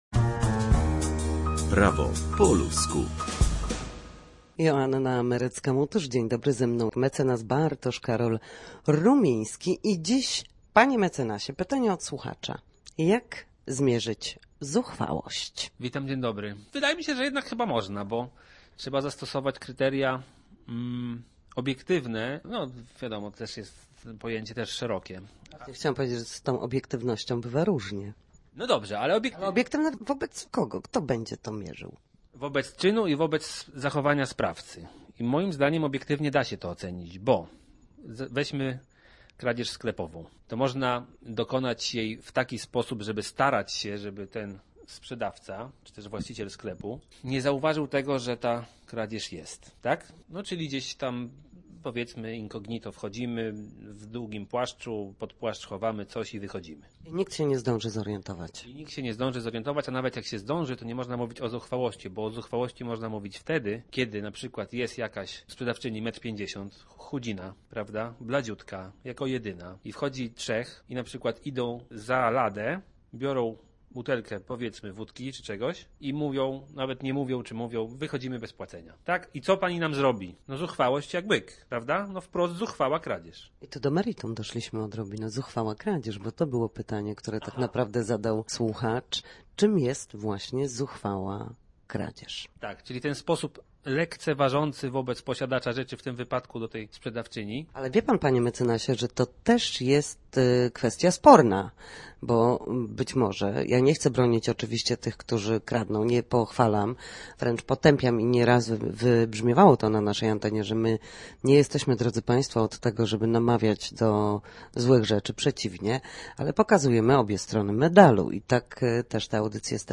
W każdy wtorek o godzinie 13:40 na antenie Studia Słupsk przybliżamy Państwu meandry prawa.